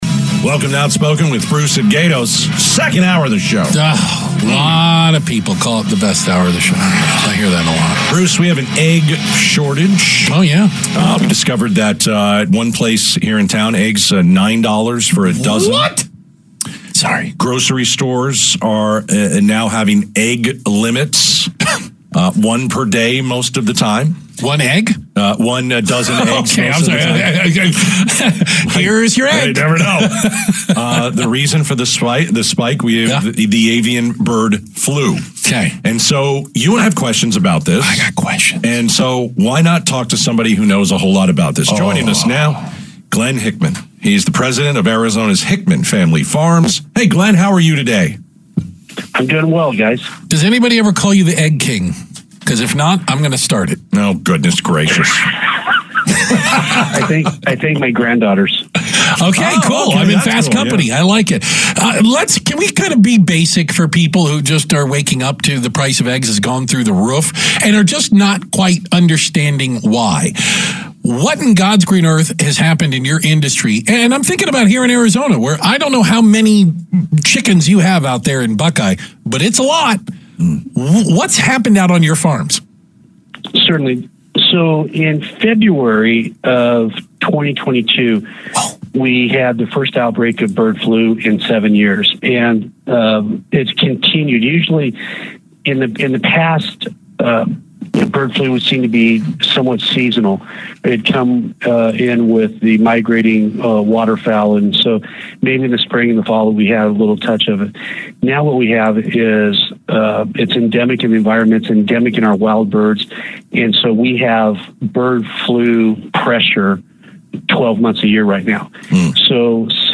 KTAR interview Feb 11 2025